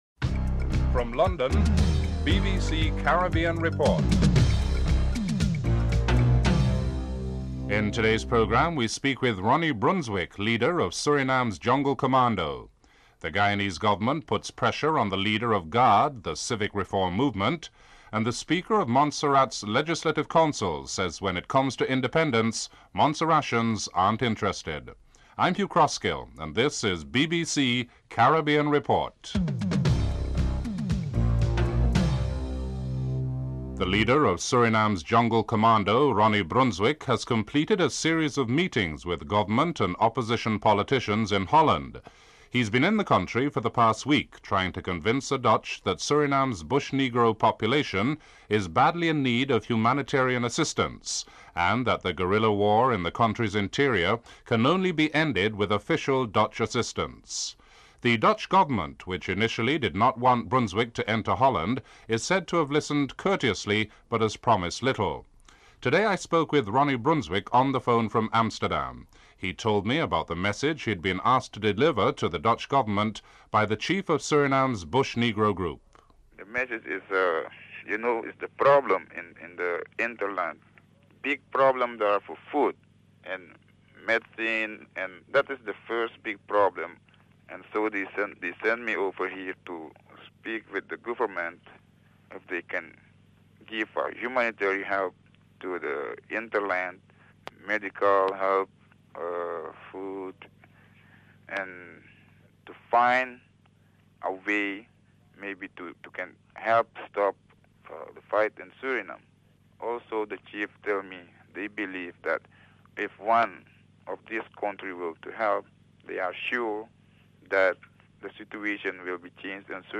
1. Headlines (00:00-00:32)
4. Financial News.